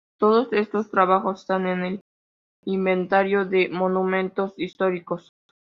in‧ven‧ta‧rio
/imbenˈtaɾjo/